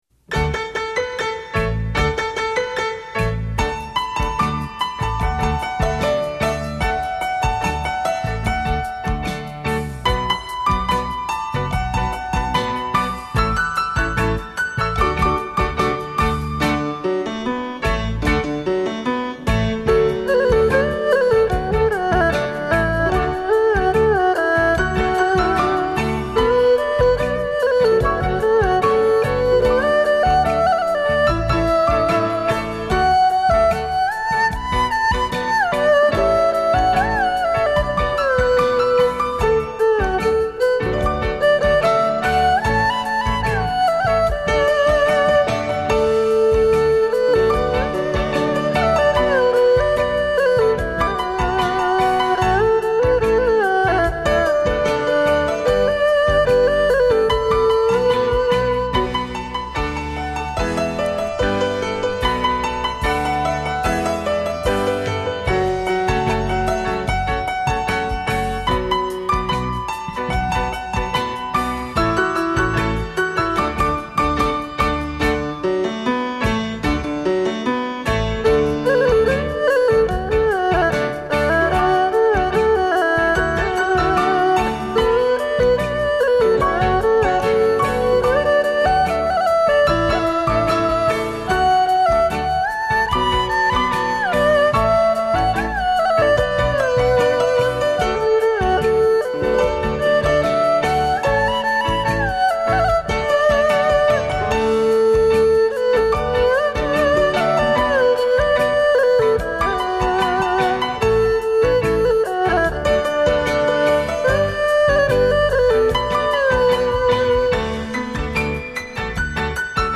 二胡演奏
国际级二胡演奏大师
诠释二胡优雅柔情
徐徐丝竹声 依如走入幽幽小径